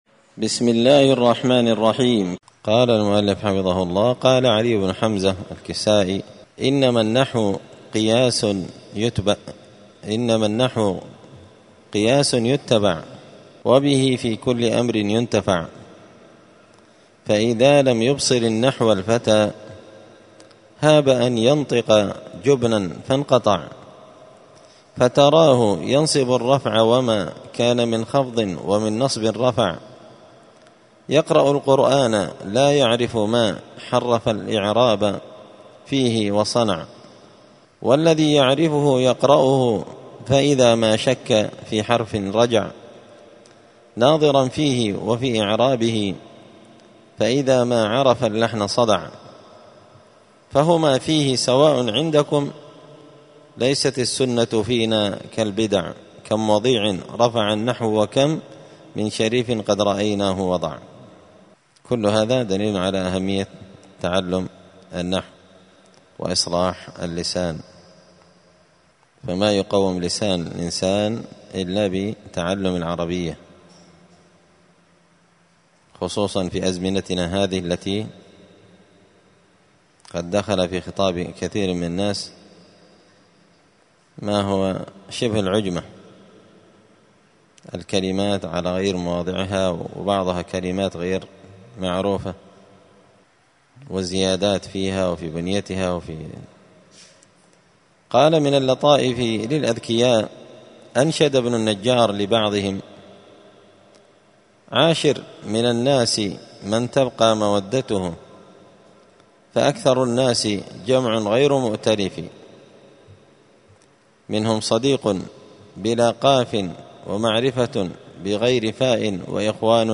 دار الحديث السلفية بمسجد الفرقان بقشن المهرة اليمن
*الدرس الثاني والثلاثون (32) طالب العلم يهتم بالنحو*